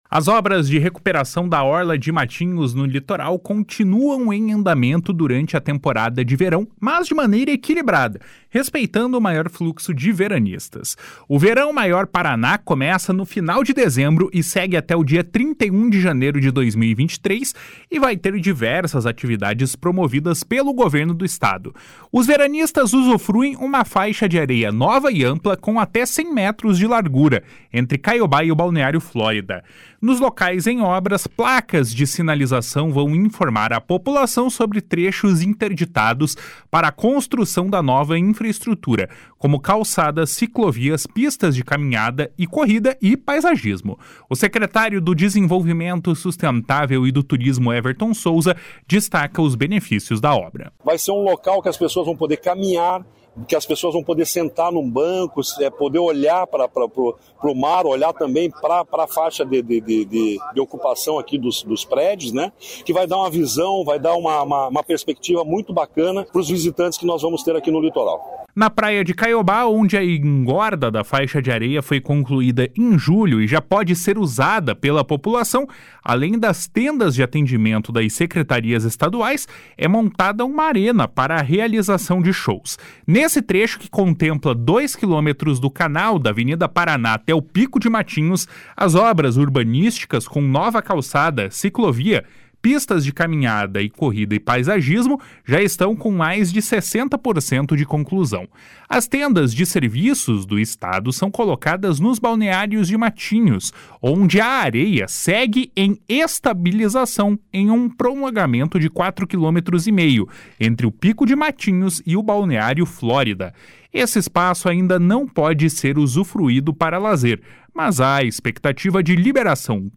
O secretário do Desenvolvimento Sustentável e do Turismo, Everton Souza, destaca os benefícios da obra. // SONORA EVERTON SOUZA //